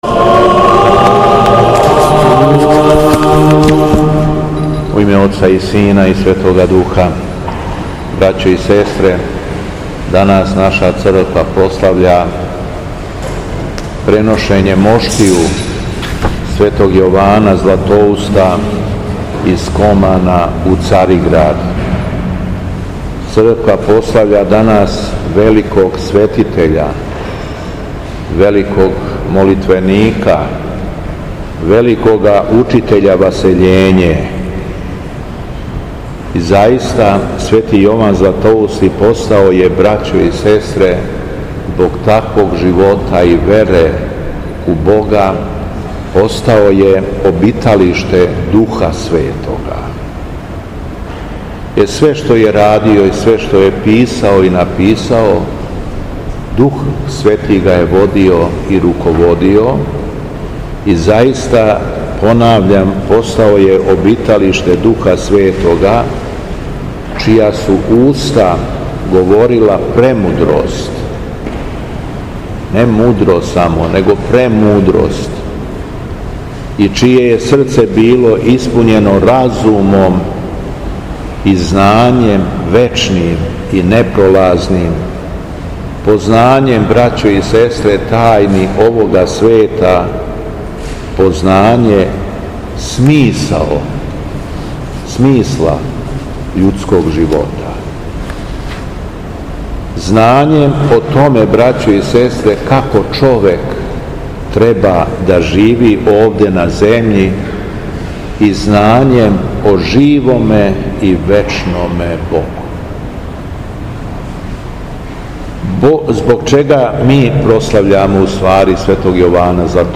Беседа Његовог Високопреосвештенства Митрополита шумадијског г. Јована
У понедељак, 9. фебруара 2026. године, када наша Света Црква прославља пренос моштију светог Јована Златоустог, Његово Високопреосвештенство Митрополит шумадијски Господин Јован служио је свету архијерејску литургију у храму Светога Саве у крагујевачком насељу Аеродром.